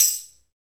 PRC TAMB 201.wav